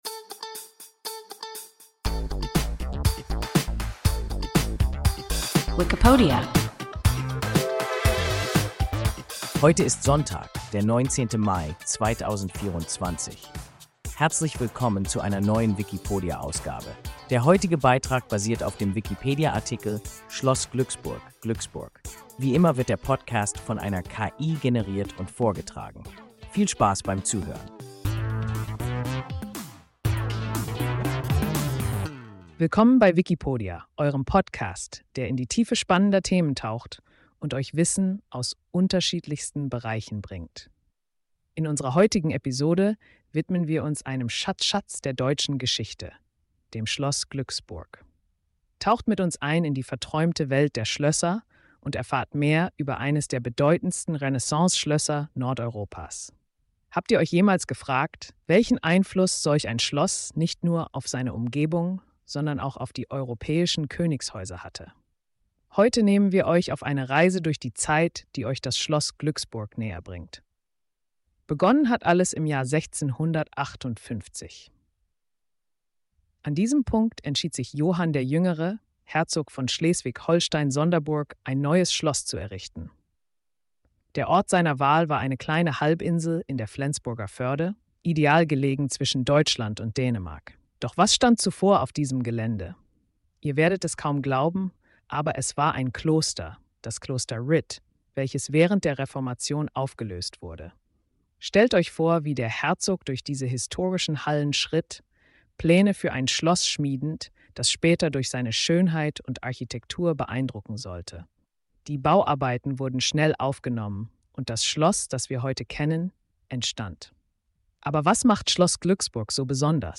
Schloss Glücksburg (Glücksburg) – WIKIPODIA – ein KI Podcast